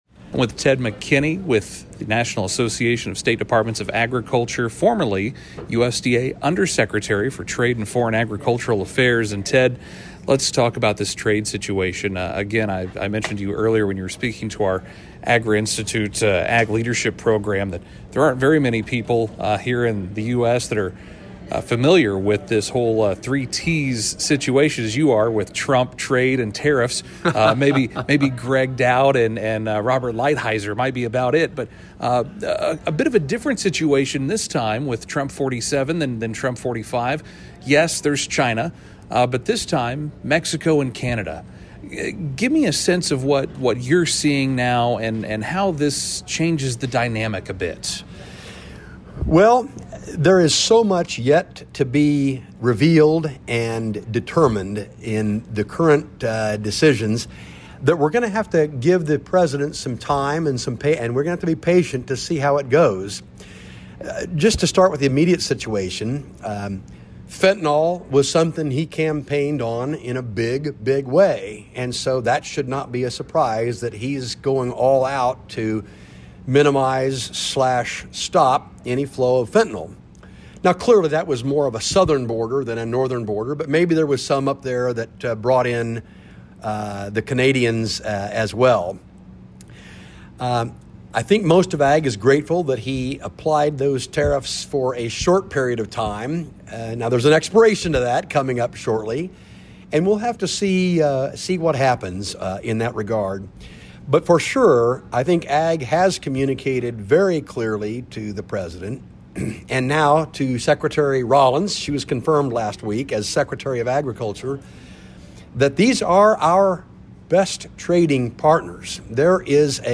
CLICK BELOW to hear Hoosier Ag Today’s full conversation with NASDA CEO and Hoosier native Ted McKinney.